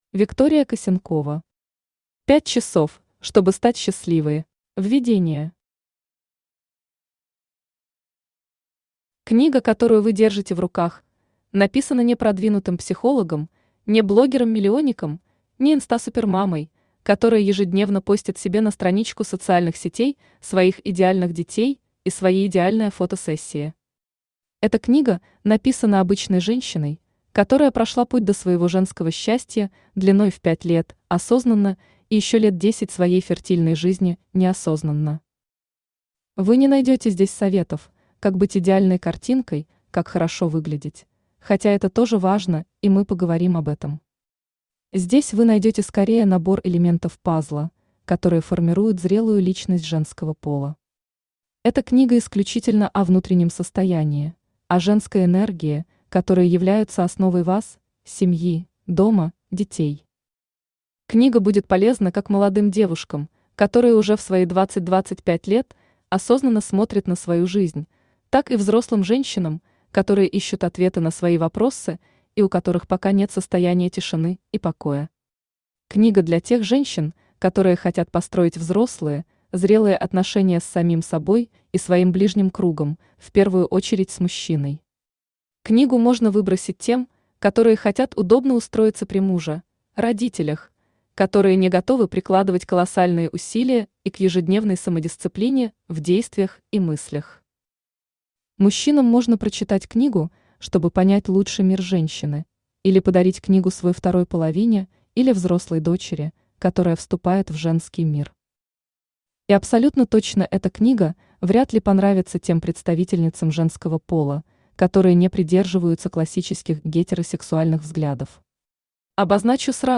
Aудиокнига 5 часов, чтобы стать счастливой Автор Виктория Косенкова Читает аудиокнигу Авточтец ЛитРес.